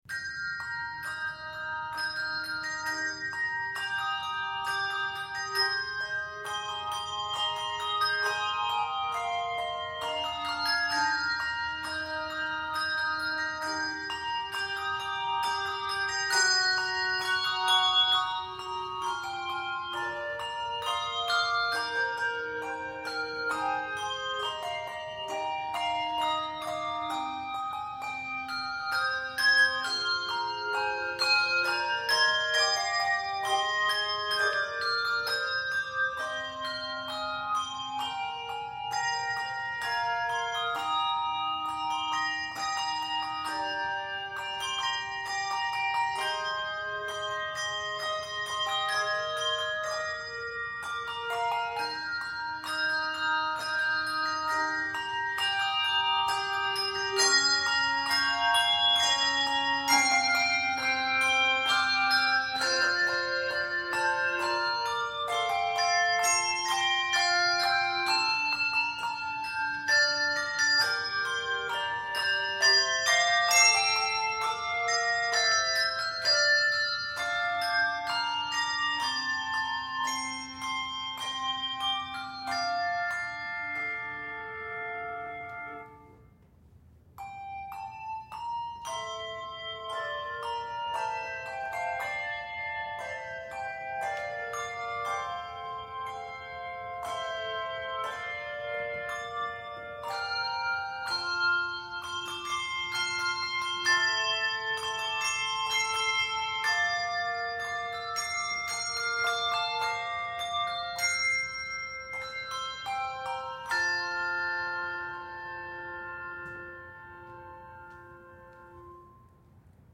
This rendition is 40 measures and is scored in F Major.